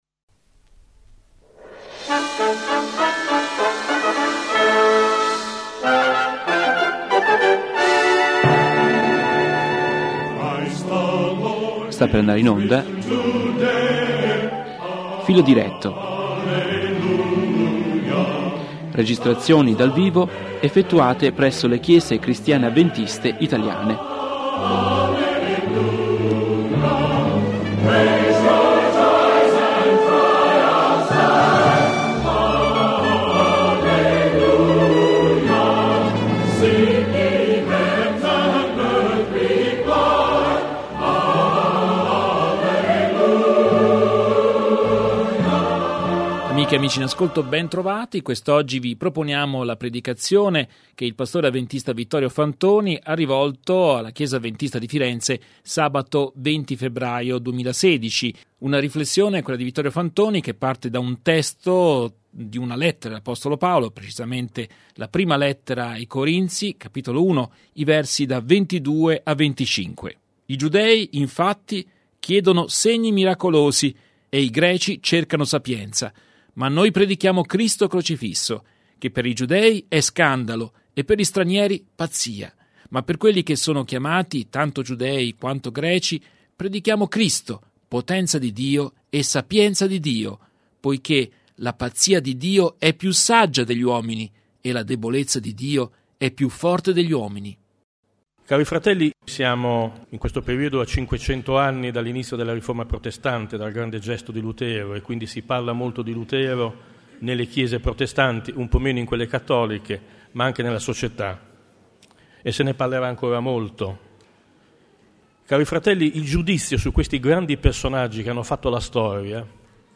predicazione